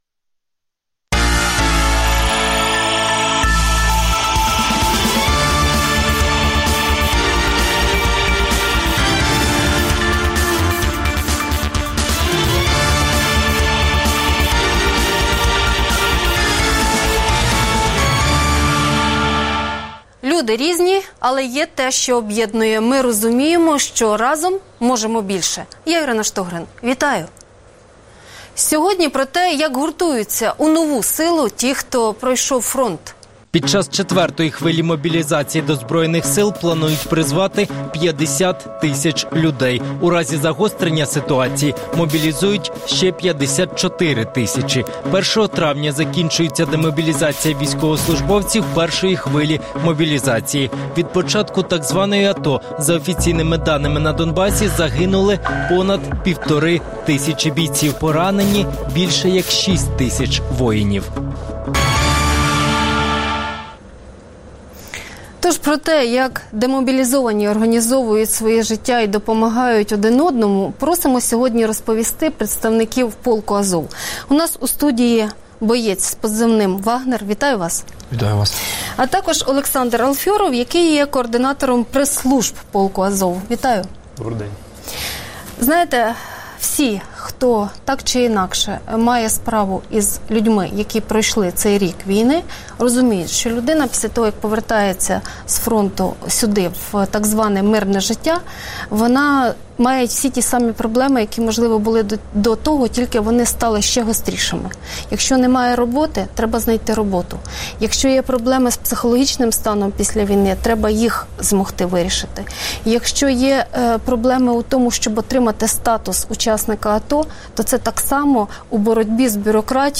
Як бійці допомагають іншим бійцям адаптуватися після війни? Гість: боєць полку «Азов»